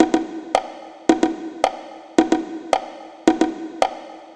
110_bongo_1.wav